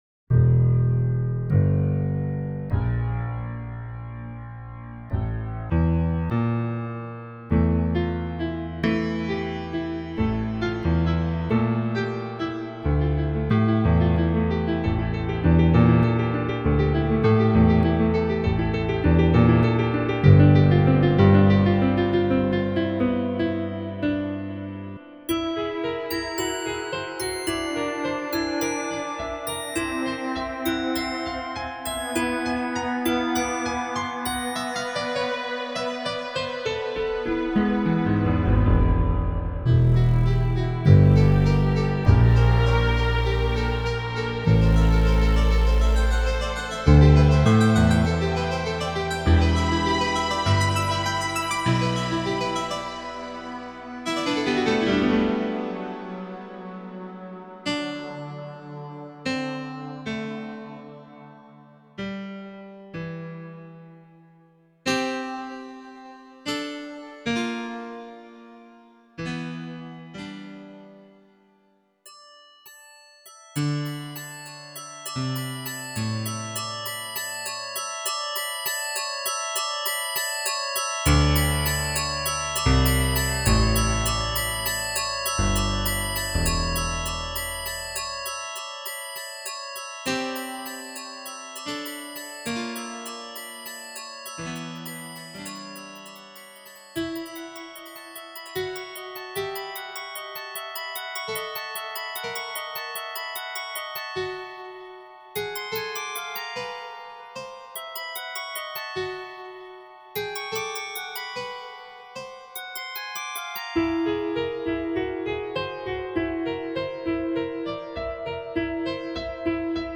This is music for synthetic piano and some bells and strings.
Or the piano might be generated out of Csound to get something different. In this case, it’s Csound.